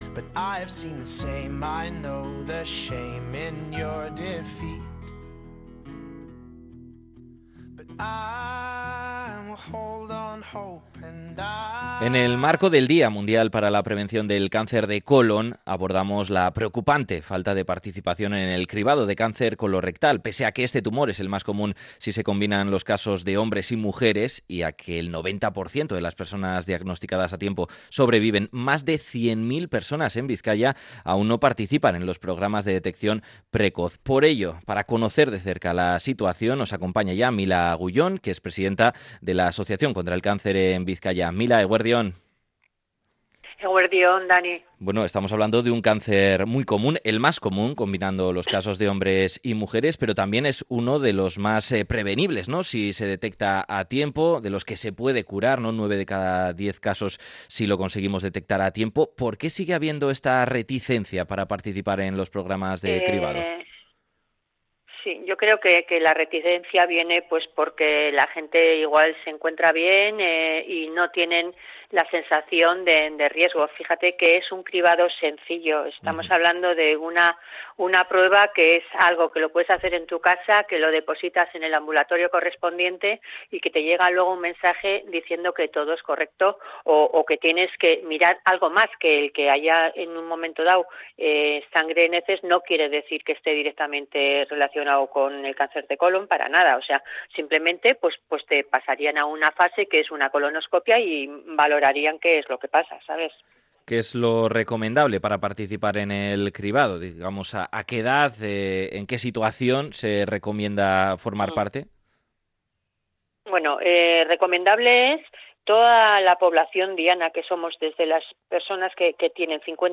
hemos hablado con